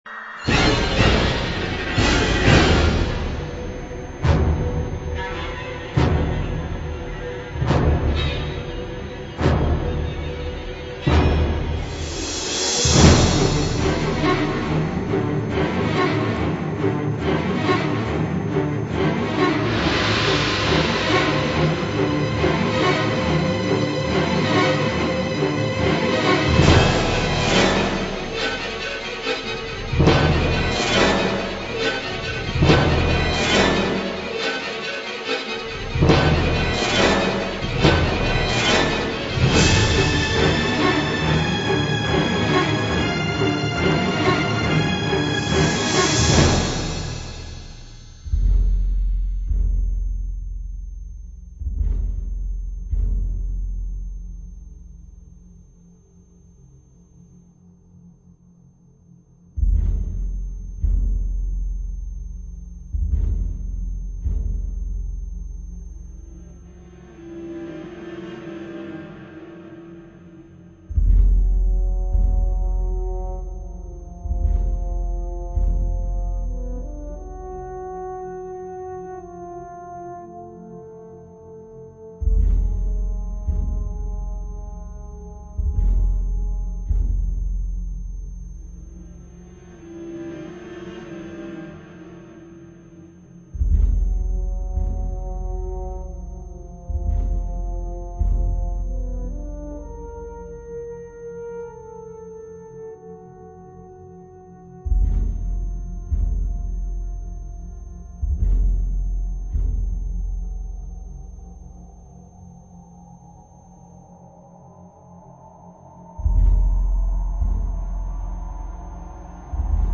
a frenzied cat-and-mouse piece